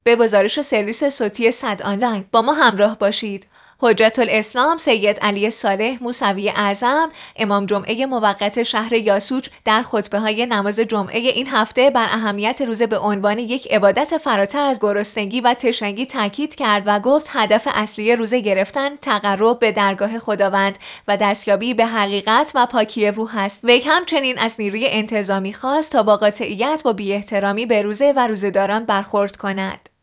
حجت‌الاسلام سید علی‌صالح موسوی‌اعظم، امام جمعه موقت یاسوج، در خطبه‌های نماز جمعه با تاکید بر اهمیت تقوا و دوری از گناه، بیان کرد که روزه باید منجر به تقرب به درگاه الهی و شناخت حقیقت شود.